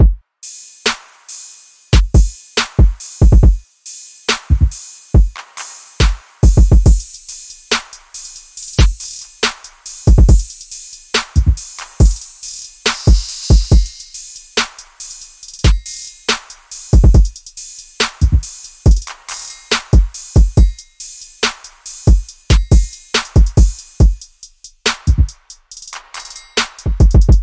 幻想 " 龙咆哮(远)
标签： 生物 WAV 远处 缠结 怪物 轰鸣 恐怖 地牢 到目前为止 恐龙 中世纪 咆哮
声道立体声